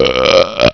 Burp
burp.wav